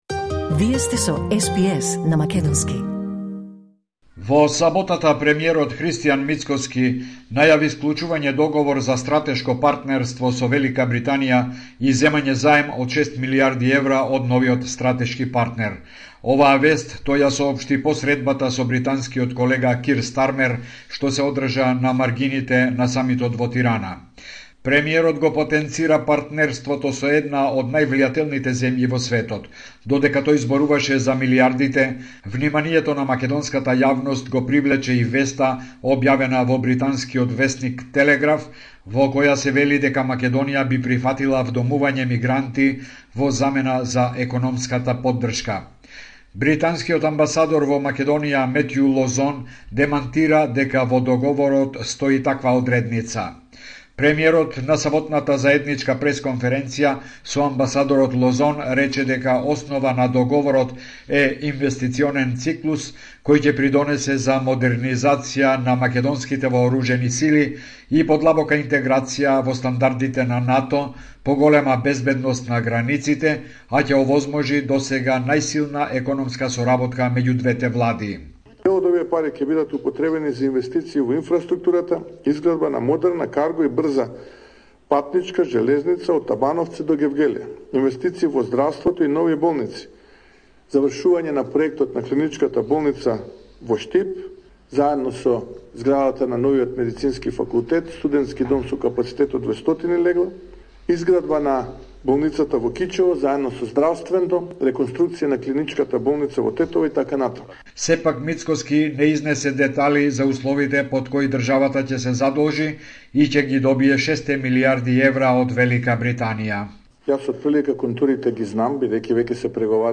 Извештај од Македонија 19 мај 2025